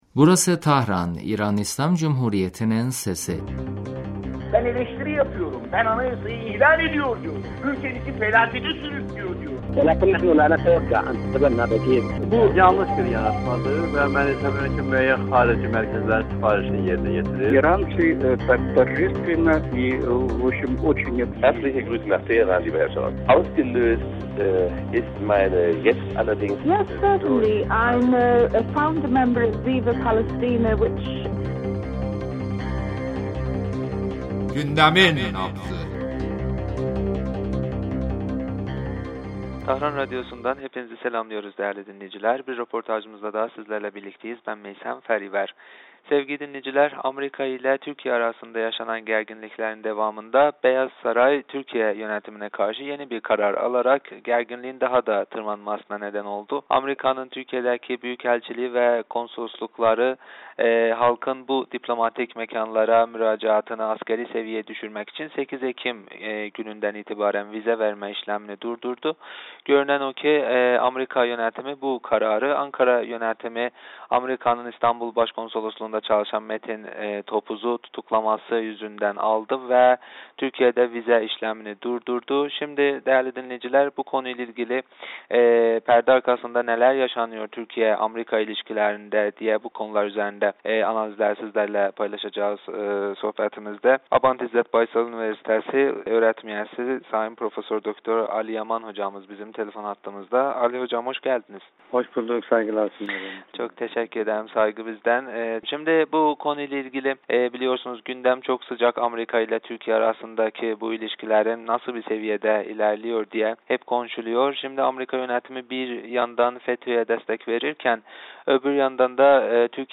Radyo